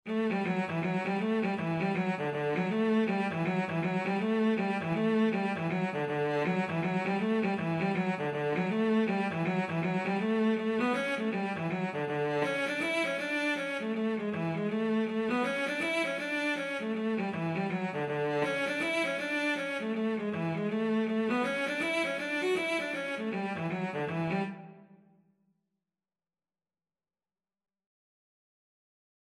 2/2 (View more 2/2 Music)
D4-F#5
D major (Sounding Pitch) (View more D major Music for Cello )
Cello  (View more Easy Cello Music)
Traditional (View more Traditional Cello Music)